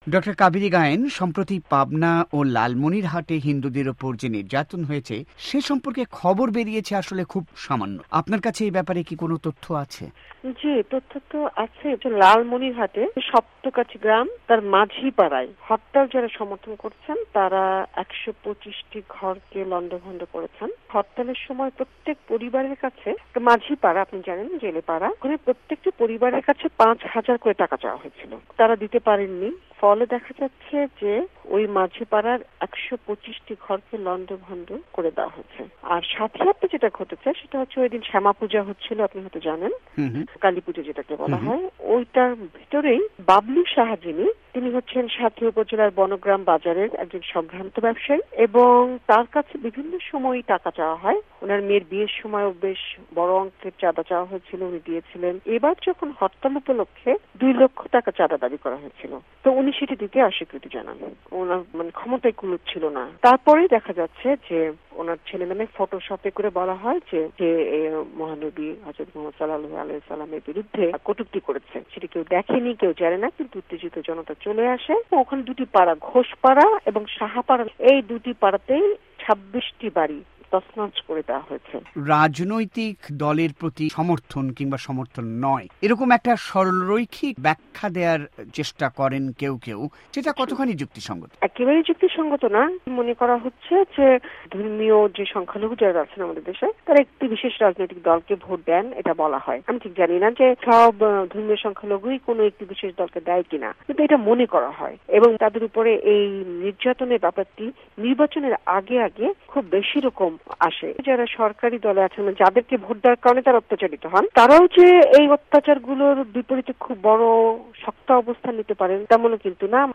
সাক্ষাতকারটি